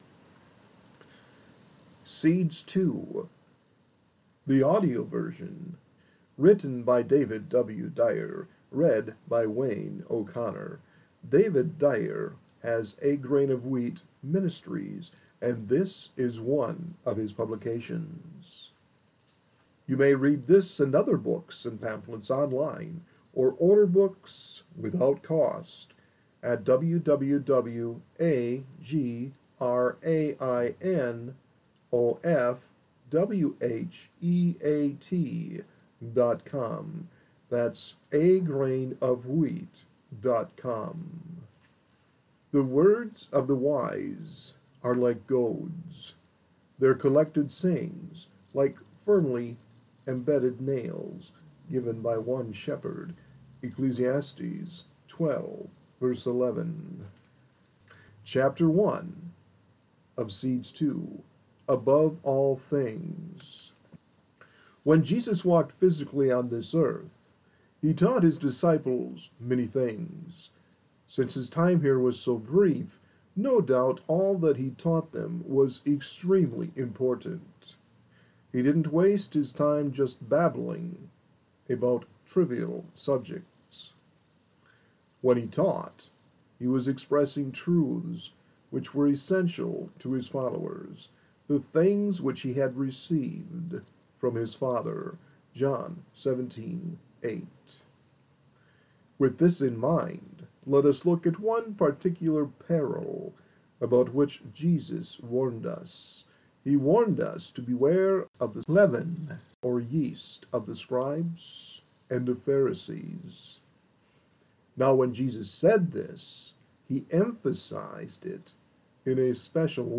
Seeds Two, The Audio Book